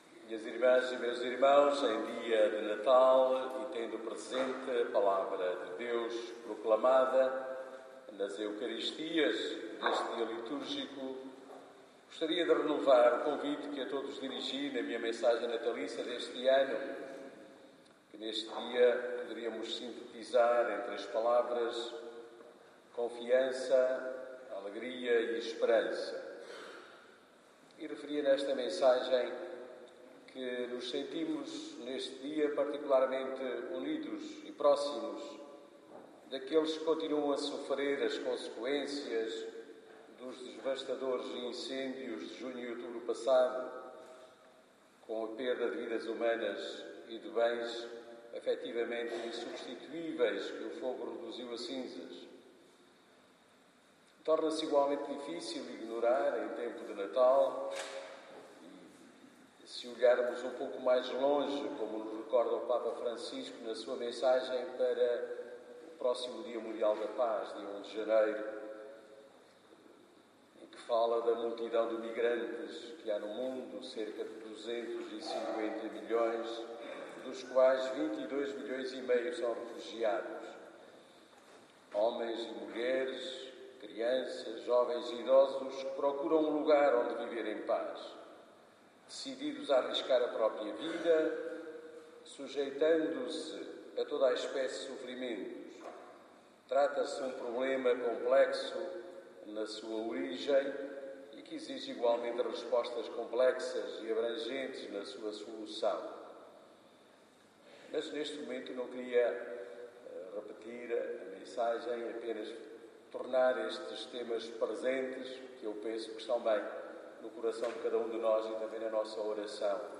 Homilia_natal_2017.mp3